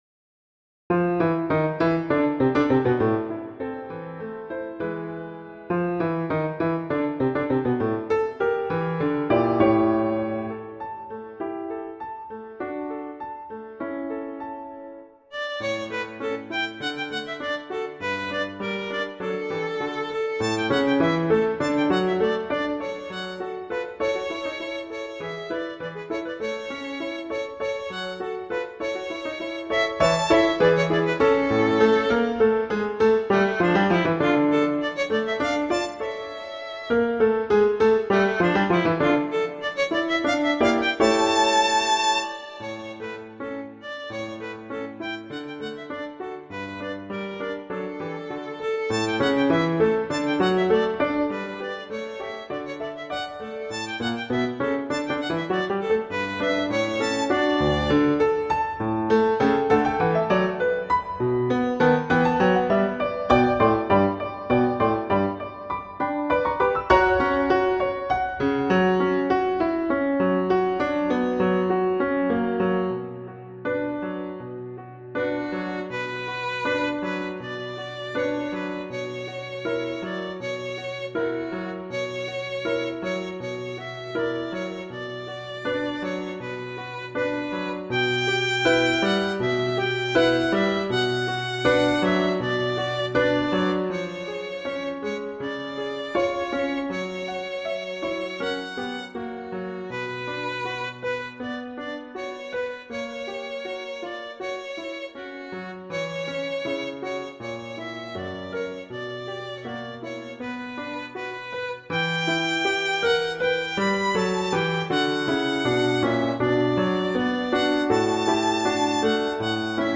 classical, concert
D major, F major
♩=70-113 BPM (real metronome 69-112 BPM)